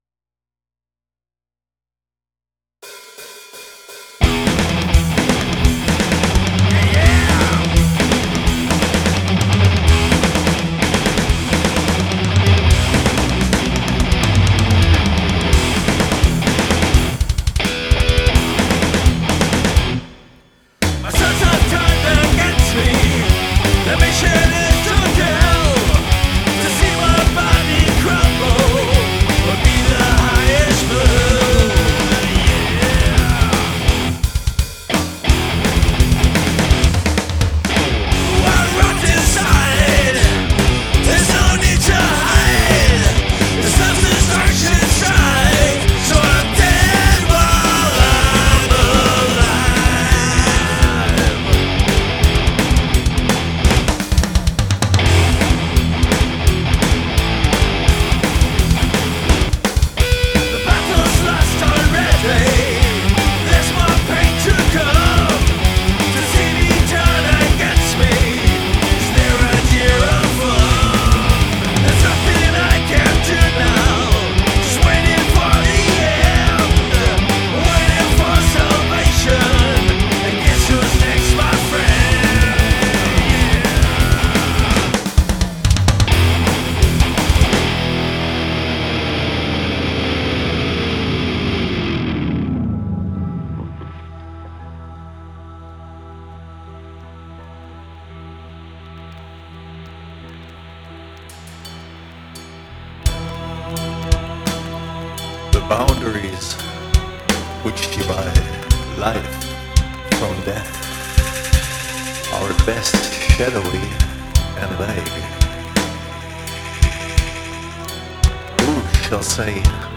I Rot Inside - Oldschool Metal [aus dem Metal Contest]
Psychedelic triffts vor allem im Mittelteil sehr gut... ist aber nicht so meins. Die Gitarren haben mir da zu wenig Gain.